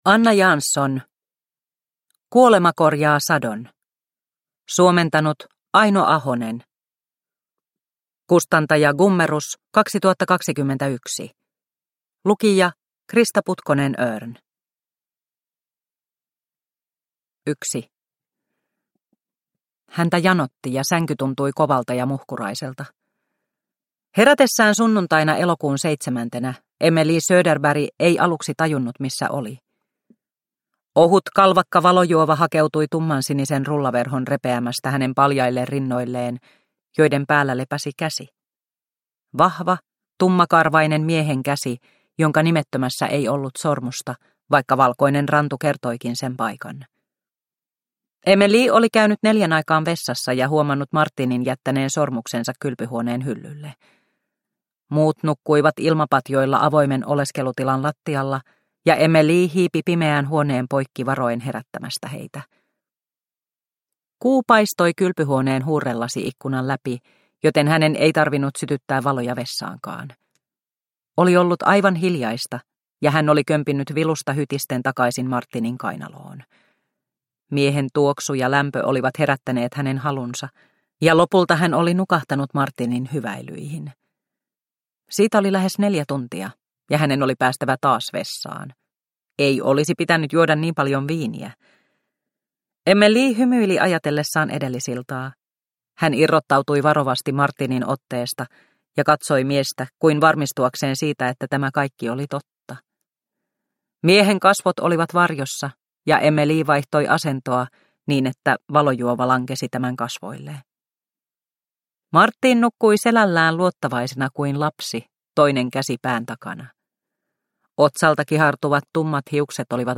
Kuolema korjaa sadon – Ljudbok – Laddas ner